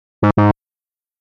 feedback-incorrect.mpeg